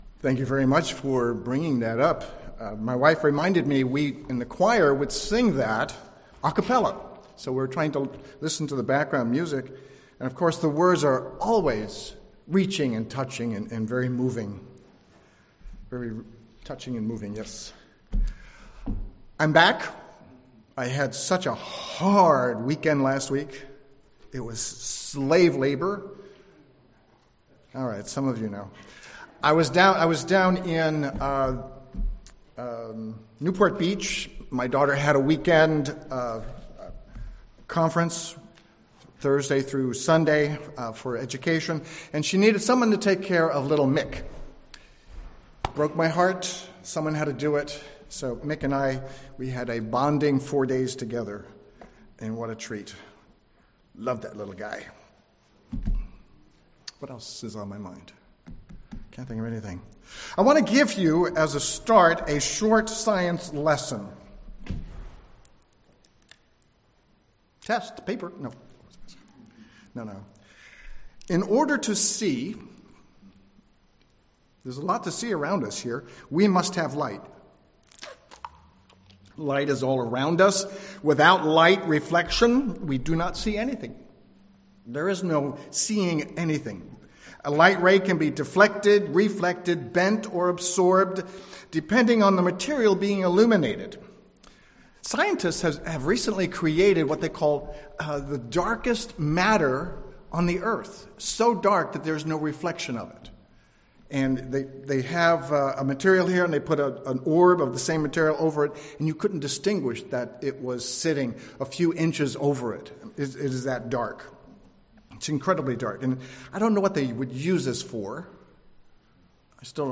Listen to this sermon and learn why we should put God's thoughts first (2 Chronicles 7:14-15). What does your eye desire (Matthew 6:22-23)?